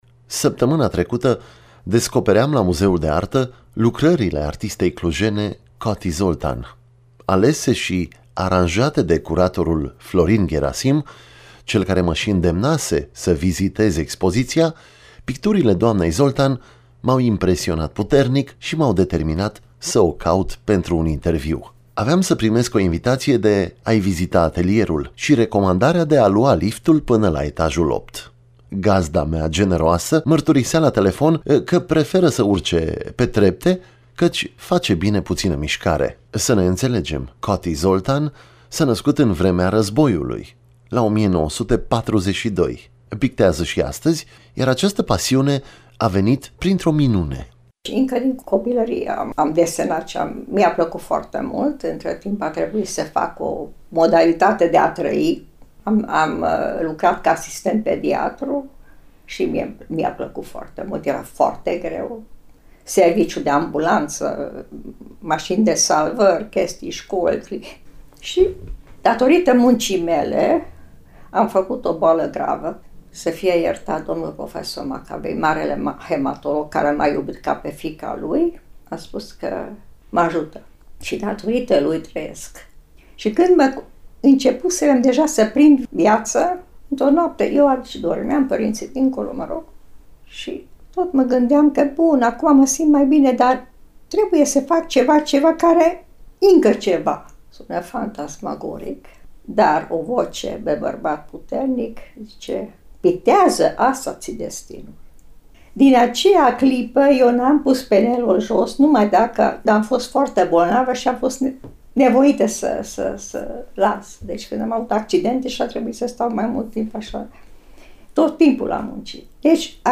interviu
A mers la ea acasă și a aflat o parte din povestea de viață a artistei: ce a făcut înainte de a deveni pictoriță, ce a făcut-o să picteze, ce influență a avut asupra ei regretatul muzicolog Iosif Sava.